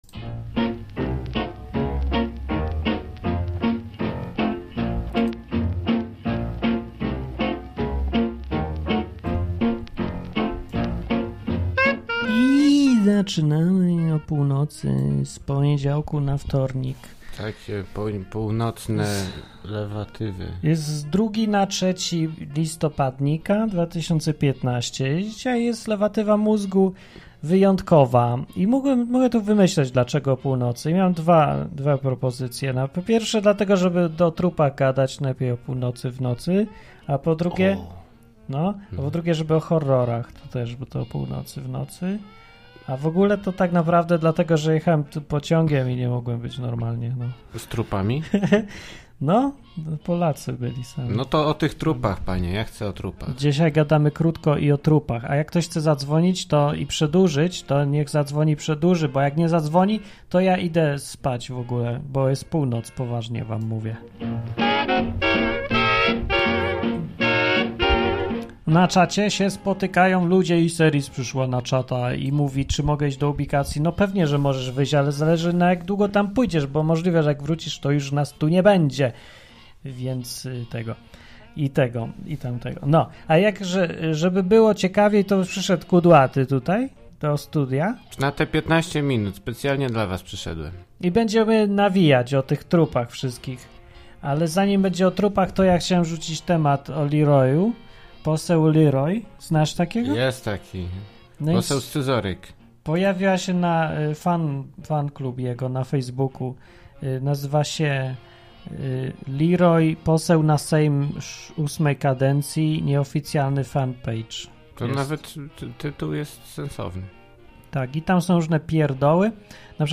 Program satyryczny, rozrywkowy i edukacyjny.